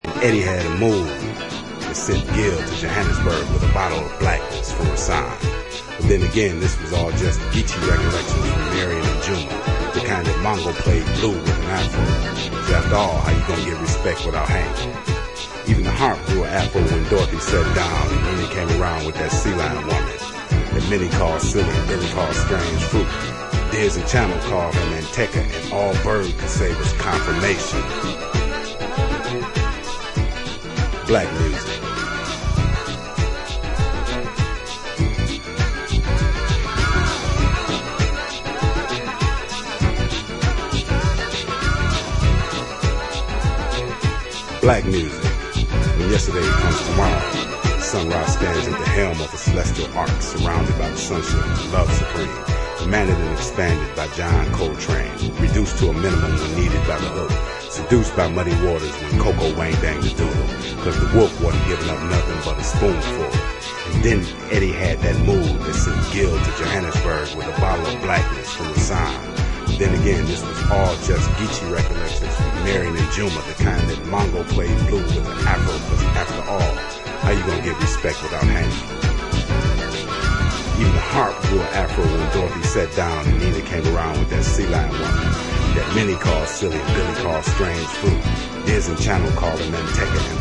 HOUSE/BROKEN BEAT